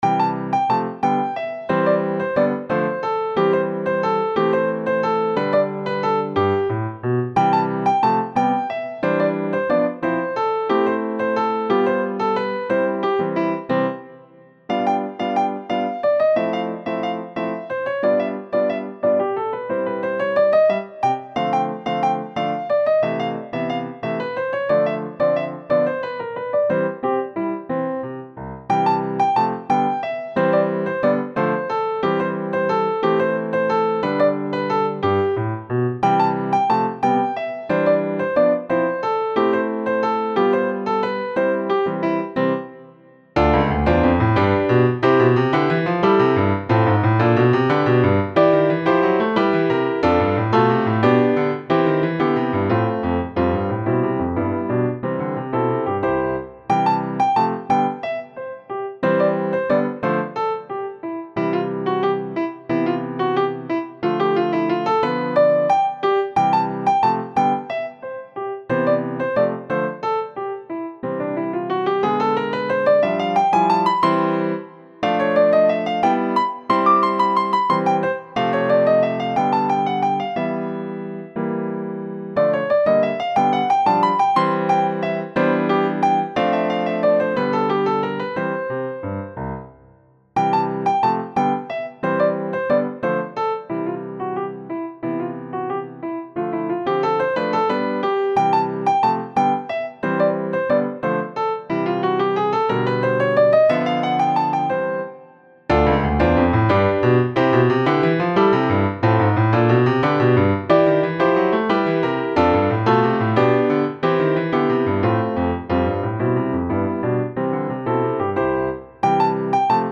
Hopscotch Solo piano, 2:11.
I fancied playing around with time signatures.
default piano